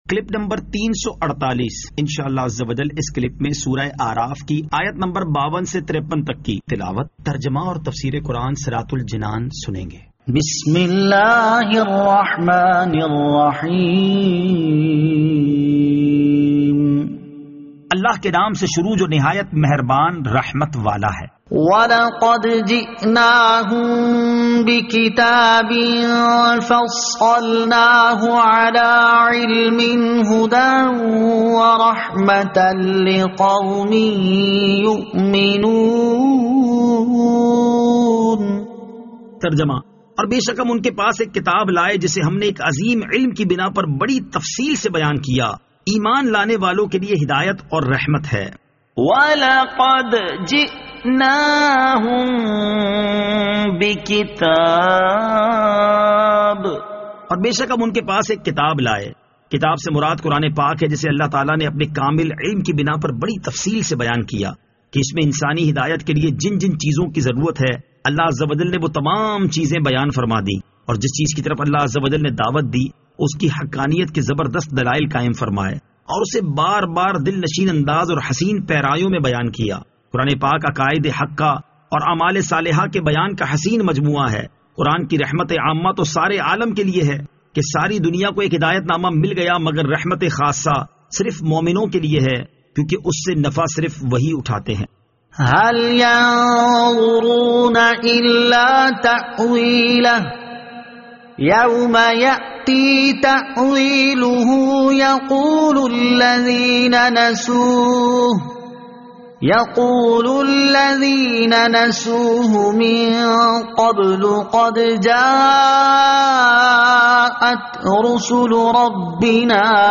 Surah Al-A'raf Ayat 52 To 53 Tilawat , Tarjama , Tafseer